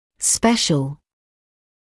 [‘speʃl][‘спэшл]особый, особенный; специальный